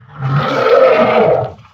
sucker_growl_4.ogg